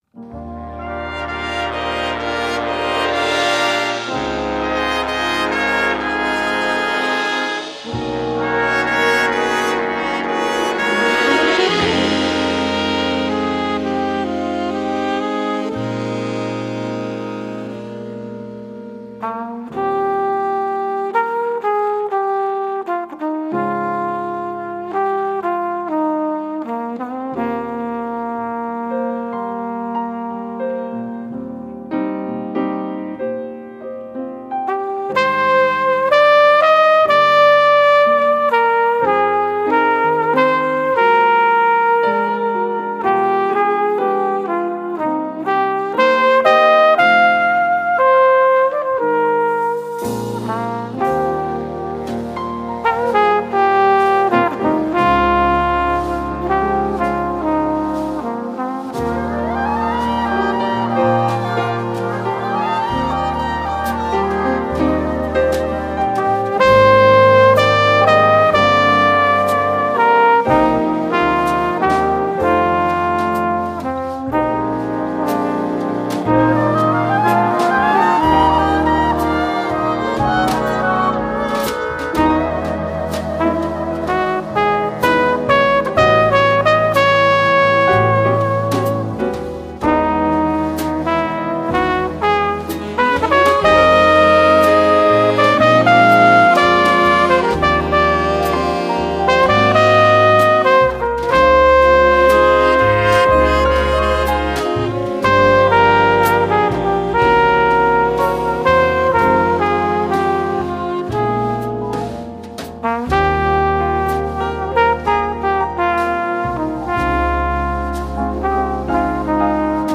Répertoire pour Harmonie/fanfare - Big Band